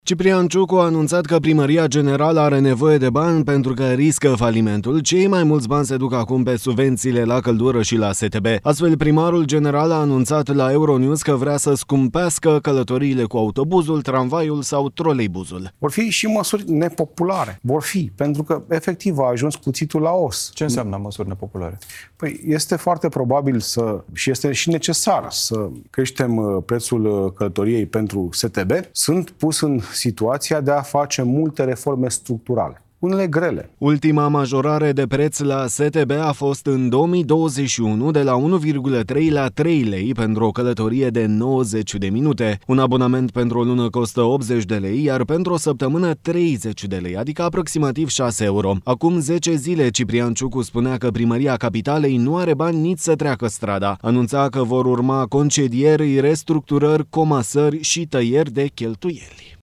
Astfel, primarul general a anunțat la EuroNEWS că intenționează să majoreze tarifele pentru călătoriile cu autobuzul, tramvaiul sau troleibuzul.